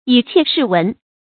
以郄視文 注音： ㄧˇ ㄒㄧˋ ㄕㄧˋ ㄨㄣˊ 讀音讀法： 意思解釋： 從縫隙里看雜色文采。